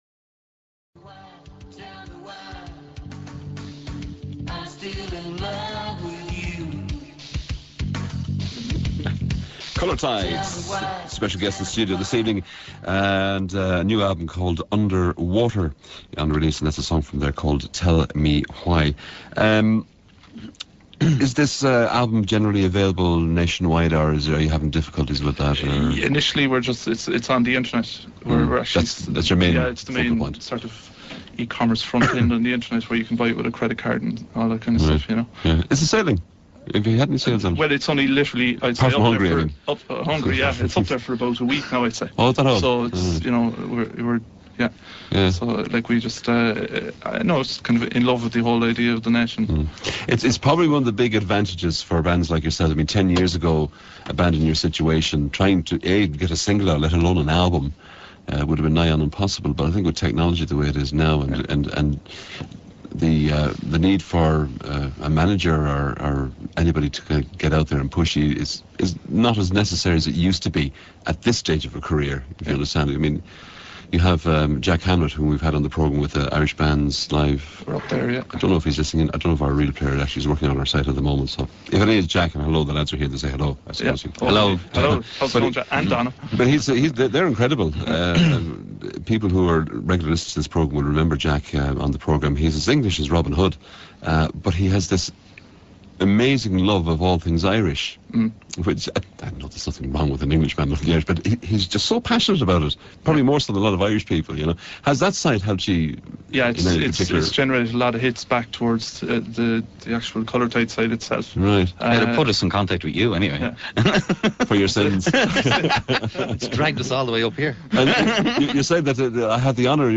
CT interview on WLR FM - Part 2a